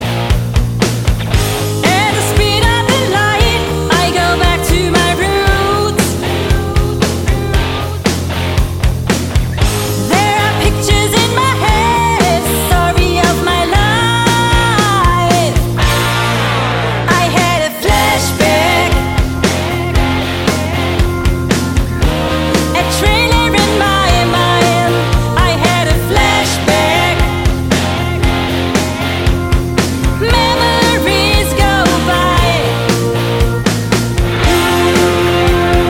Gesang
Gitarre
Bass
Schlagzeug) ihre Leidenschaft für Rock- und Popmusik.